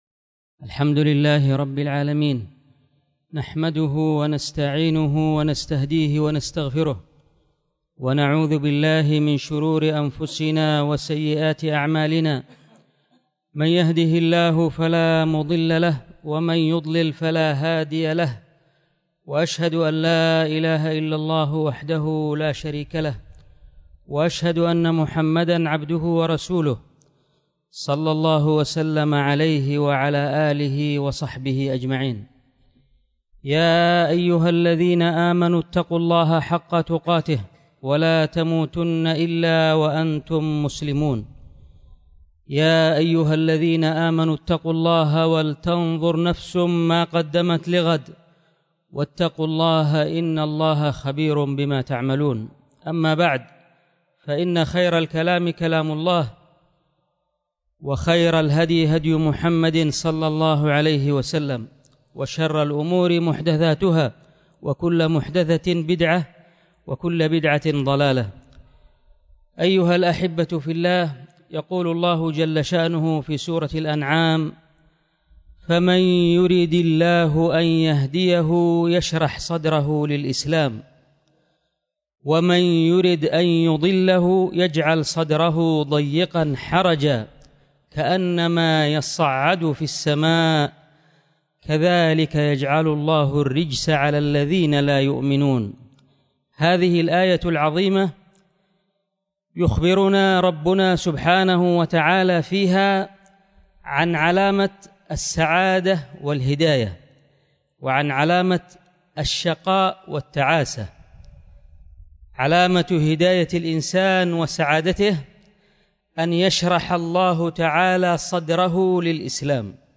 الخطبه
خطب الجمعة